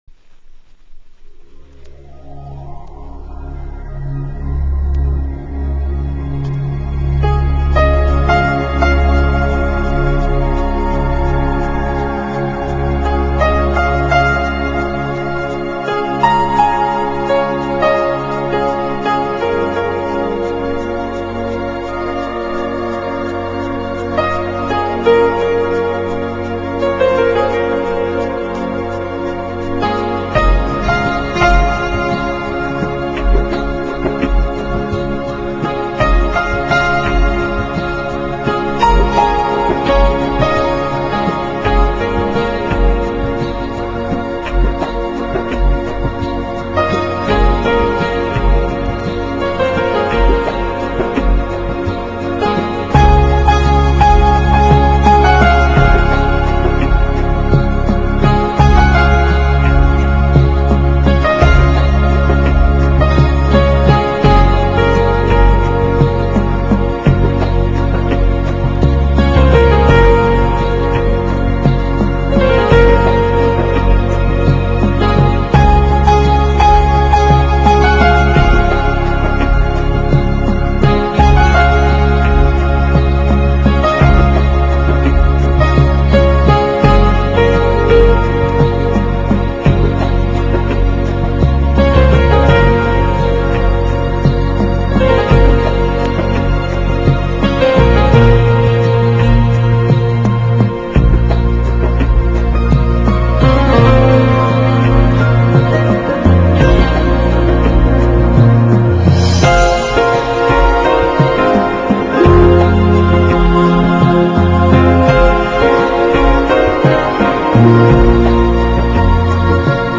Chill Out系の傑作!!